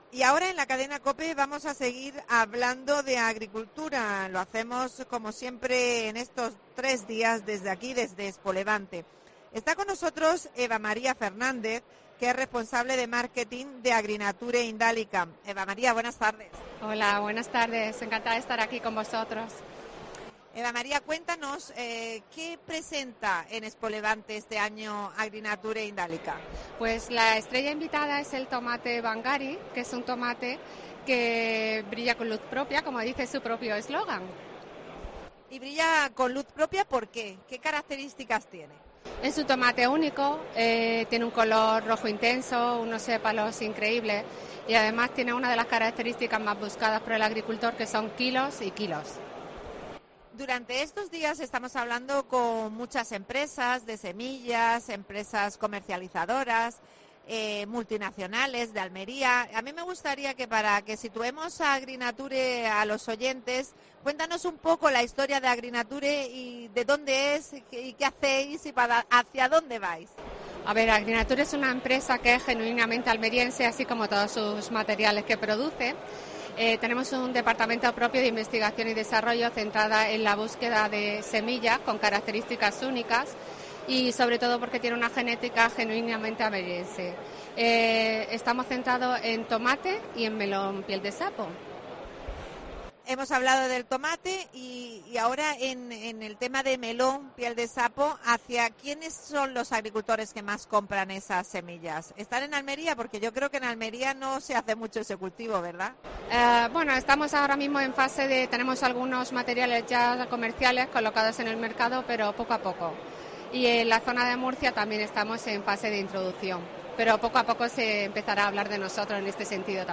AUDIO: Especial ExpoLevante. Entrevista